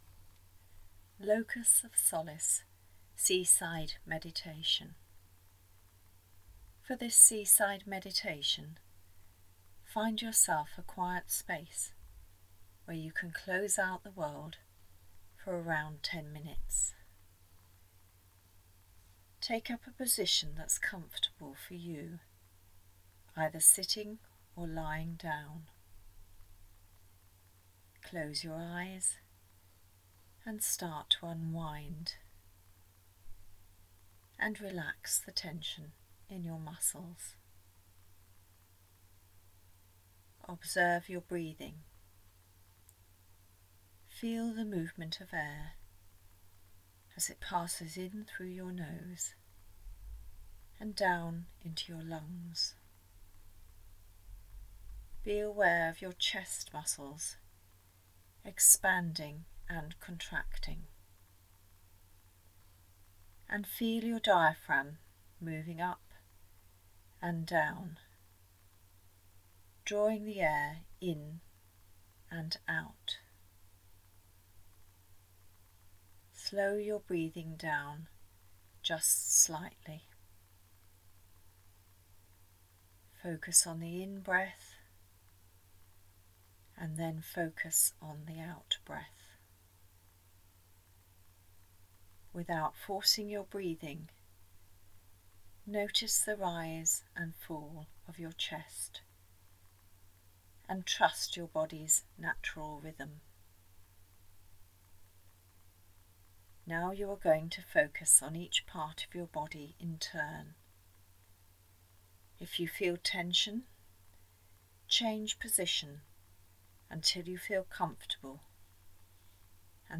Locus of Solace Seaside Shell Meditation
locus-of-solace-seaside-shell-meditation.mp3